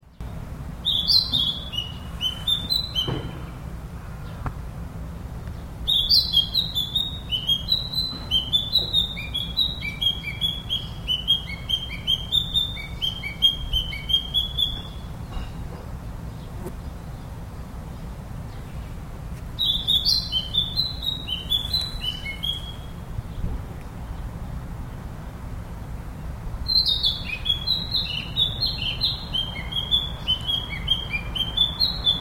数日前から、渡りの途中と思われるキビタキが吉香公園に滞在しています。
黄橙色、黒。白のコントラストが美しい鳥で、しかも大変澄んだよく通る声でさえずります。
今日は写真撮影とあわせて美しい声も録音してきましたので、いっしょにお楽しみください。
≫ キビタキがさえずる声（MP3ファイル約500KB）